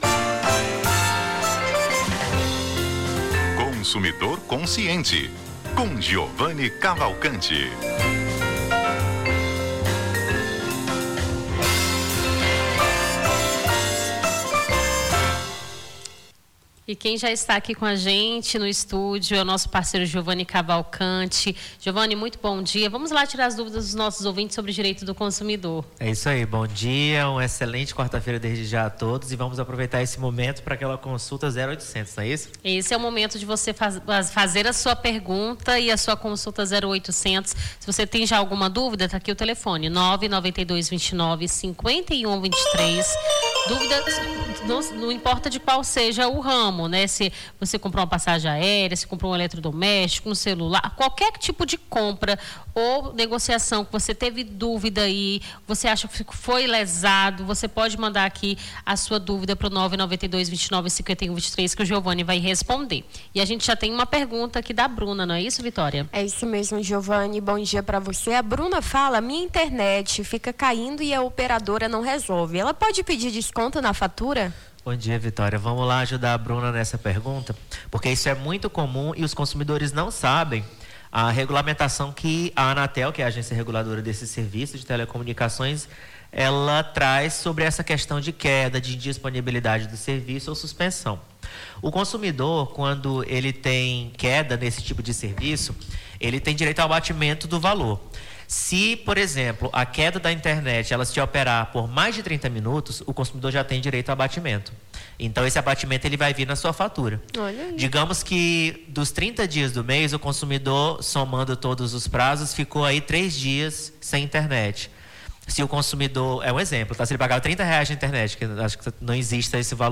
Consumidor Consciente: advogado tira dúvidas sobre direito do consumidor